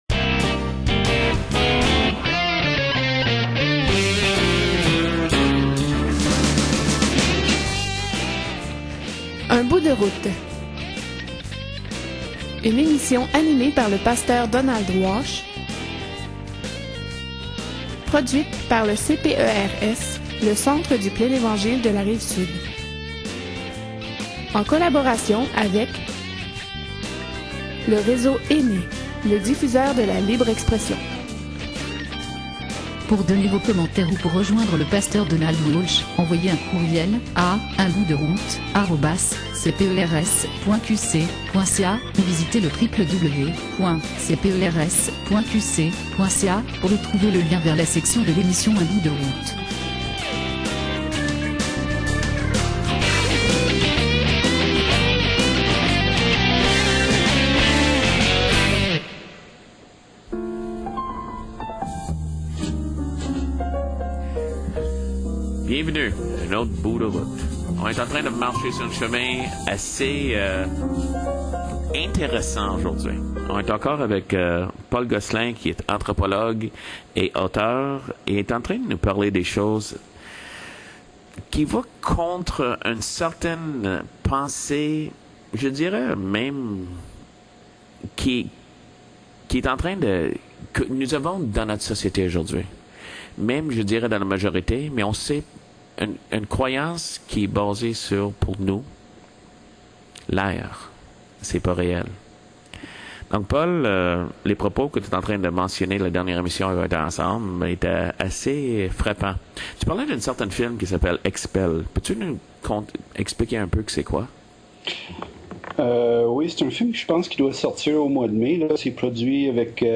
Entretiens et entrevues